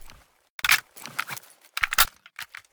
gauss_reload.ogg